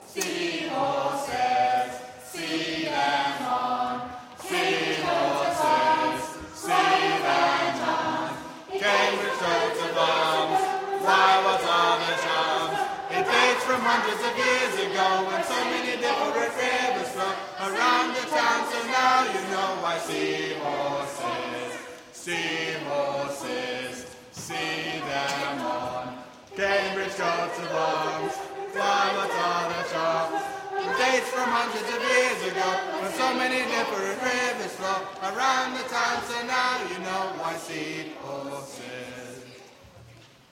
Singing History Concert 2016: Sea Horses 2
Lyrics below: To the tune of Three Blind Mice LYRICS Sea hors-es, See them on, Cambridge Coat of Arms, Why?